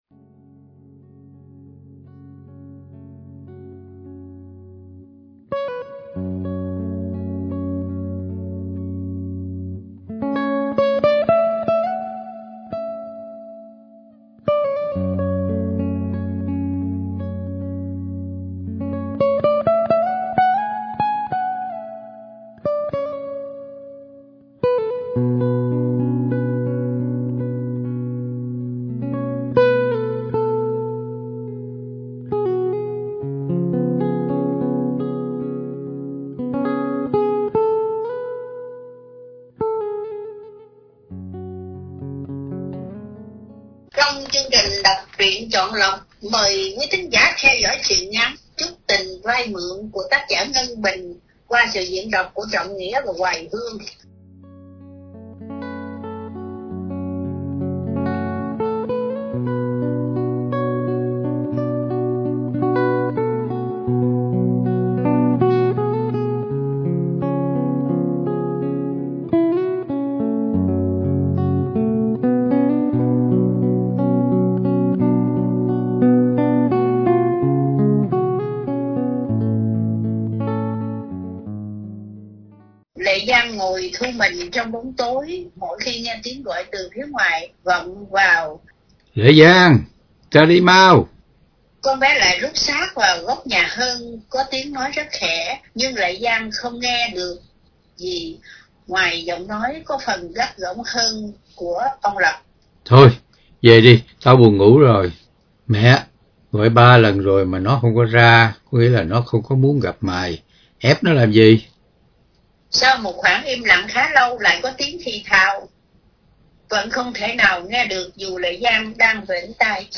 Đọc Truyện Chọn Lọc – Truyện Ngắn “Chút Tình Vay Mượn” – Ngân Bình – Radio Tiếng Nước Tôi San Diego